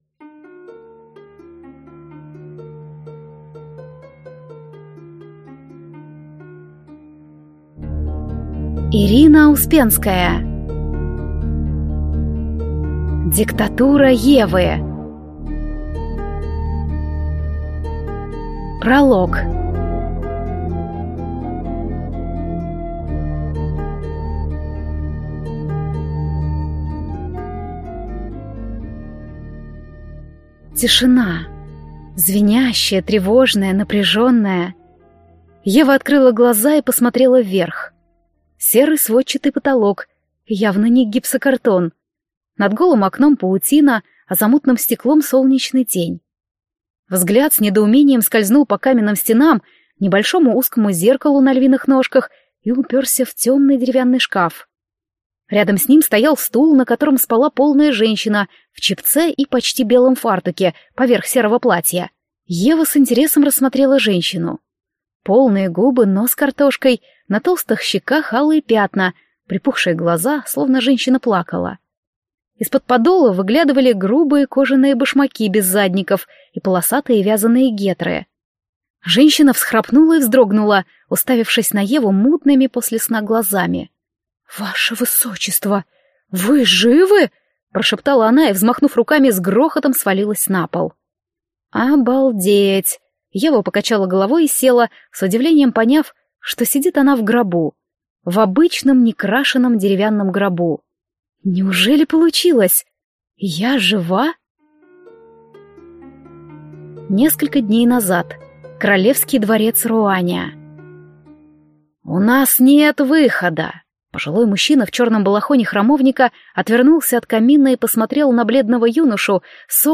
Аудиокнига Диктатура Евы | Библиотека аудиокниг